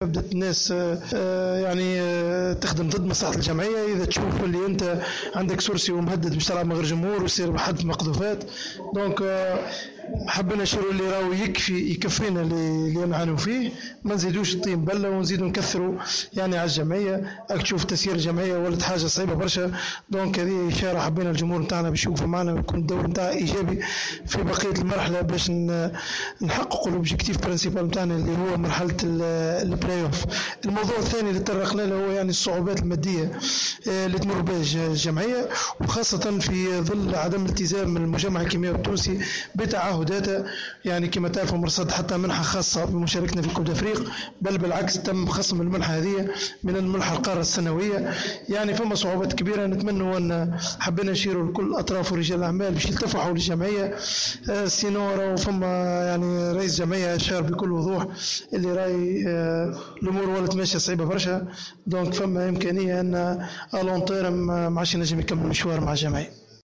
ندوة صحفية للملعب القابسي